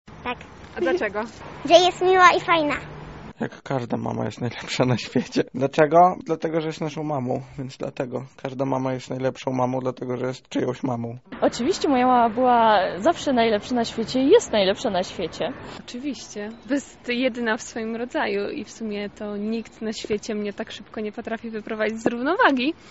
Zapytaliśmy mieszkańców Lublina, czy według nich ich mamy są najlepsze na świecie:
sonda-1.mp3